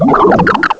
pokeemerald / sound / direct_sound_samples / cries / duosion.aif
-Replaced the Gen. 1 to 3 cries with BW2 rips.